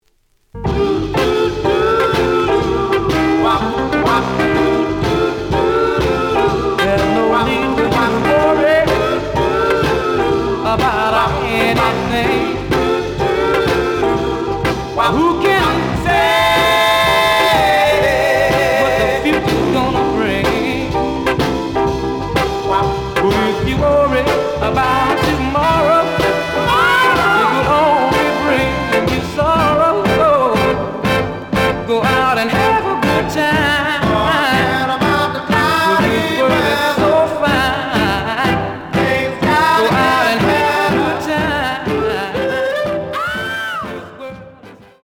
The audio sample is recorded from the actual item.
●Genre: Soul, 60's Soul
Slight edge warp.